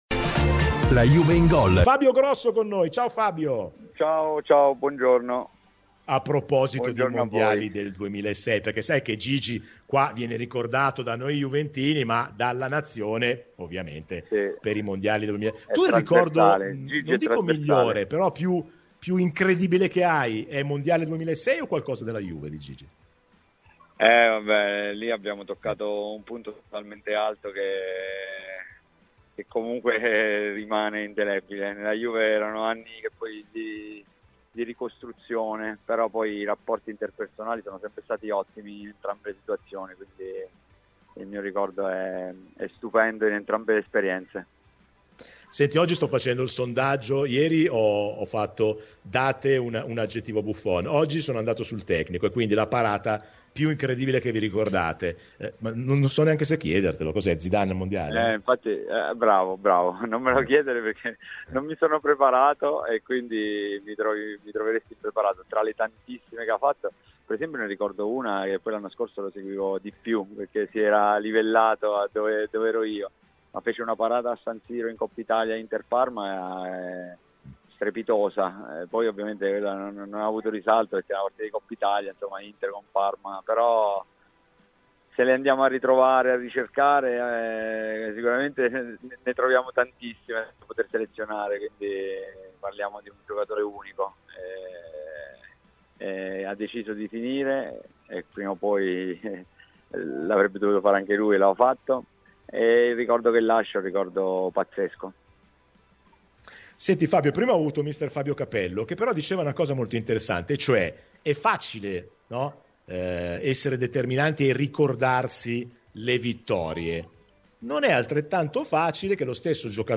Ex giocatore e allenatore delle giovanili della Juventus e Campione del Mondo del 2006 da assoluto protagonista, Fabio Grosso è stato ospite oggi di “La Juve in Gol” su Radio Bianconera e ha voluto omaggiare Buffon dopo che il portiere ha annunciato il proprio ritiro: “Con Gigi ai Mondiali abbiamo toccato il punto più alto della nostra carriera e ovviamente per me la partita più incredibile è quella che fece in finale su Zidane, ma lui ha sempre mantenuto livelli altissimi, ad esempio anche l'anno scorso ha fatto una parata straordinaria a San Siro in Coppa Italia contro l'Inter, non ha avuto risalto ma è da rivedere.